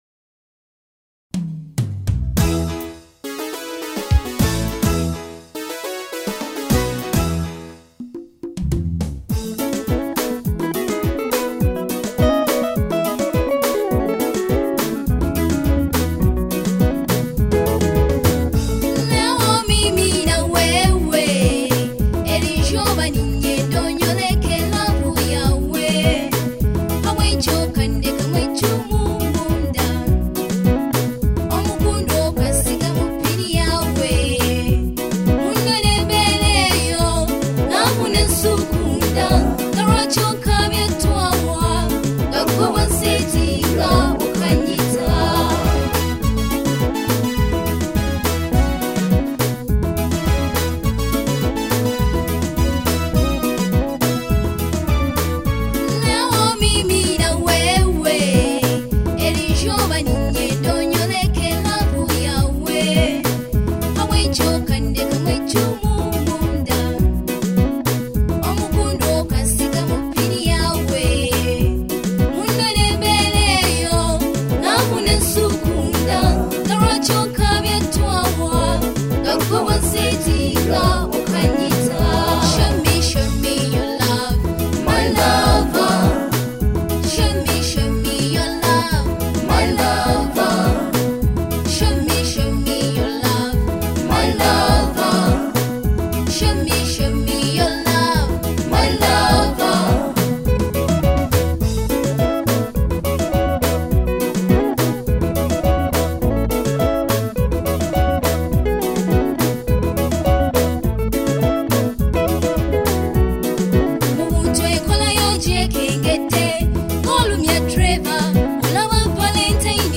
other songs from Kadongo Kamu single's